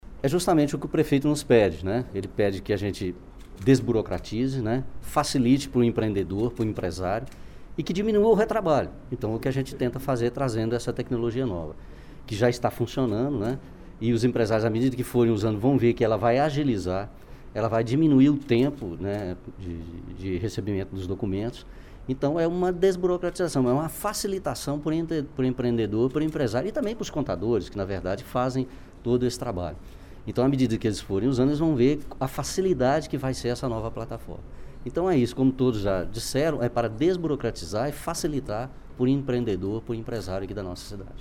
José Leonardo Martins Pinto é o secretário Municipal de Gestão Fazendária e está nesta ponta, coordenando esta liberação dos alvarás.